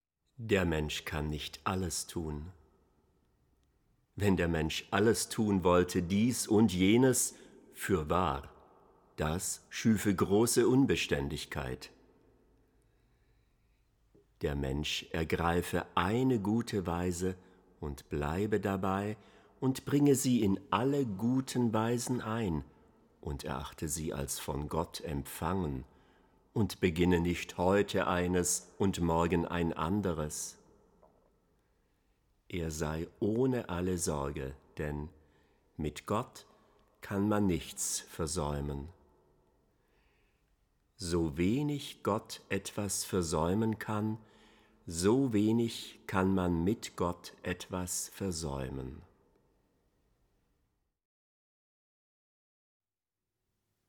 lesen Texte des großen Mystikers Meister Eckhart (1260 – 1328)
Rezitation